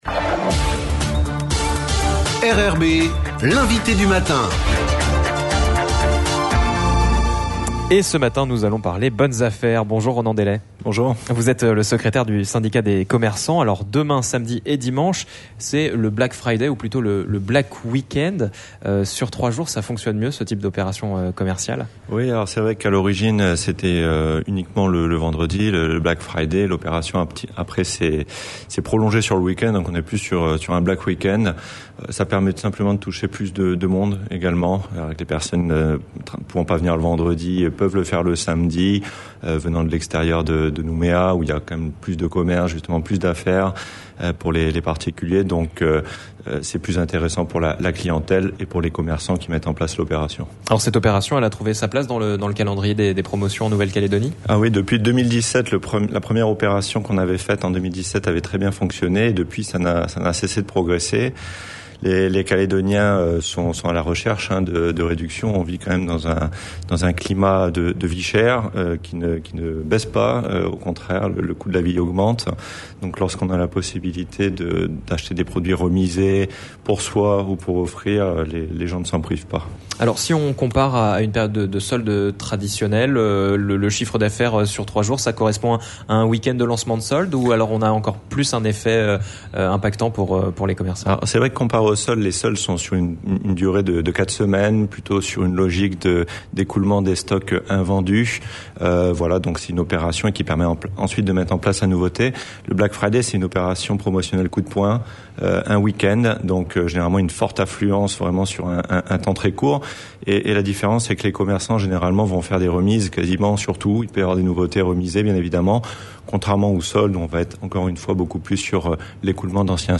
L'INVITE DU MATIN : JEUDI 24/11/22